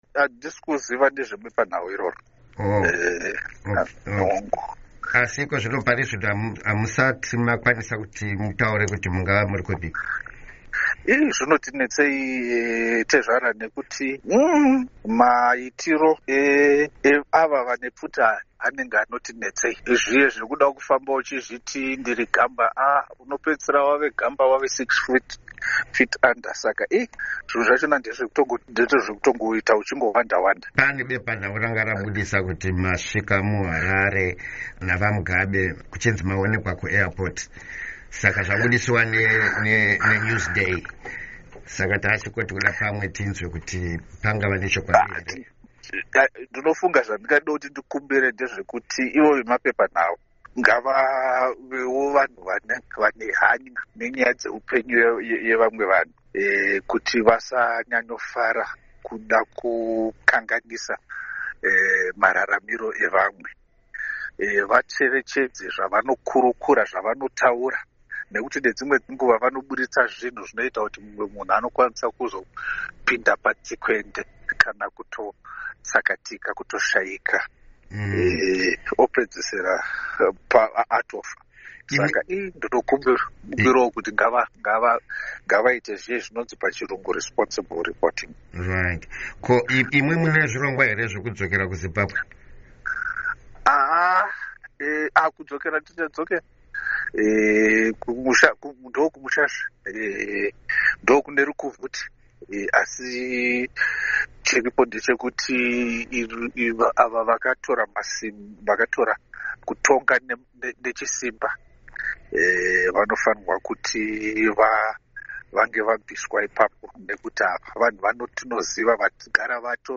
Hurukuro naVaPatrick Zhuwao